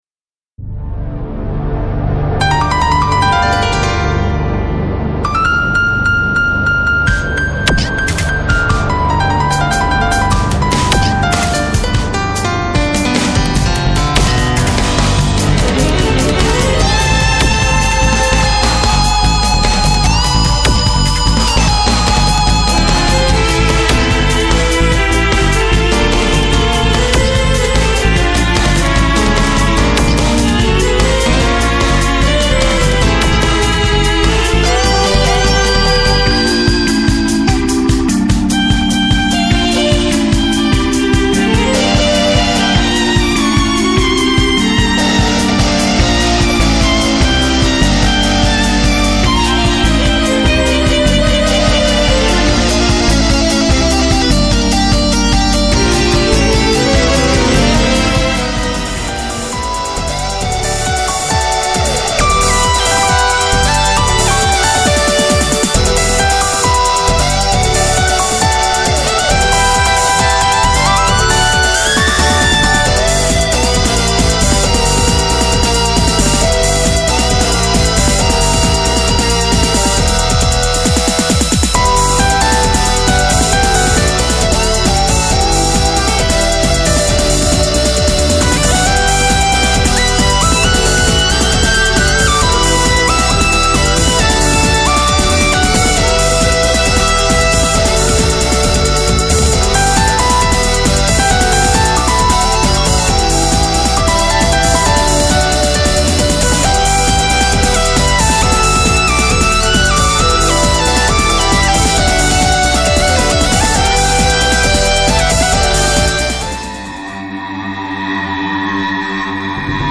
※試聴版は以下の曲の、頭の50～60秒程度つないだサンプルです。